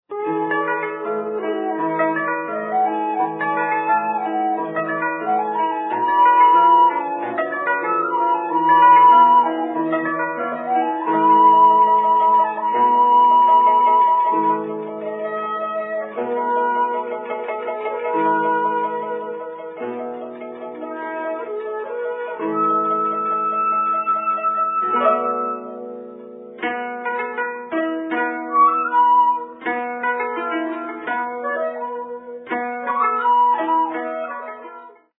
koto pieces recorded in Japan
featuring virtuoso performances